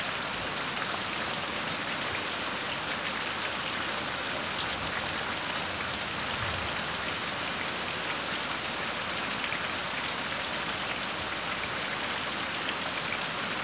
Rain_Medium_Loop.ogg